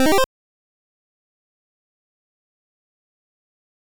snd_jump.wav